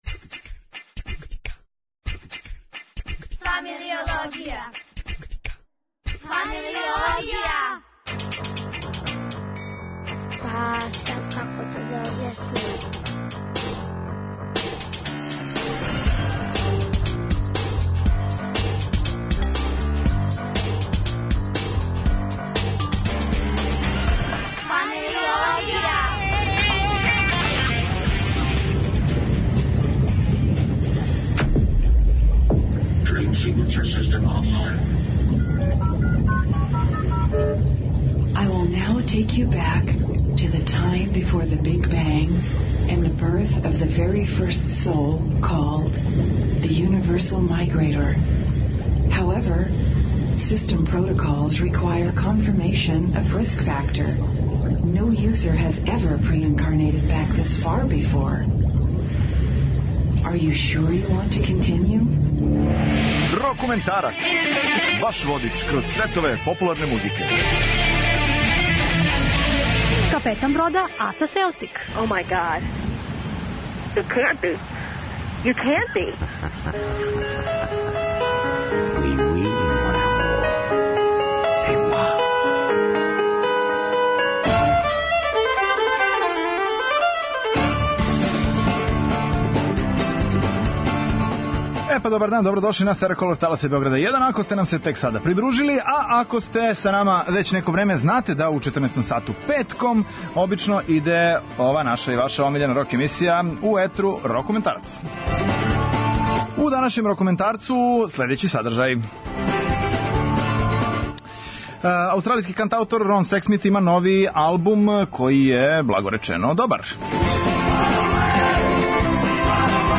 У овом 'Рокументарцу' слушате следеће нумере: